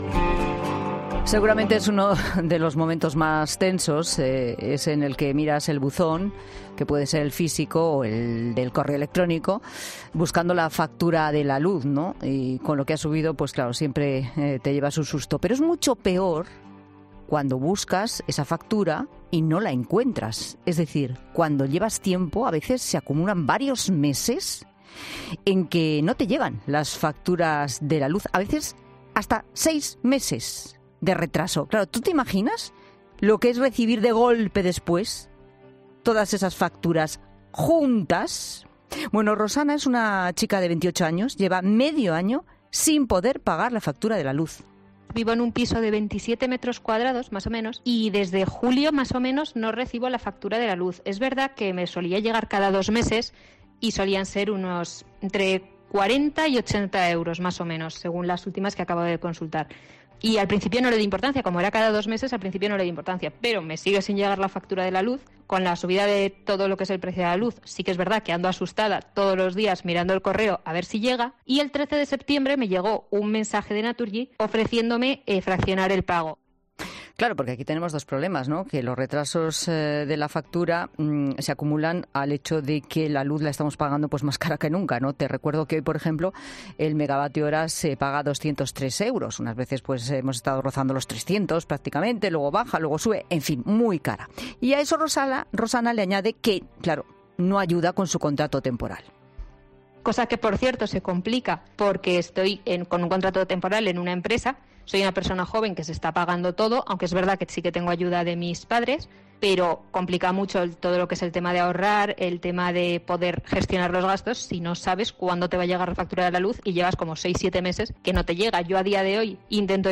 'La Tarde' ha hablado con una joven que lleva seis meses sin recibir la factura de la luz y con un experto que ha explicado qué hacer en esta...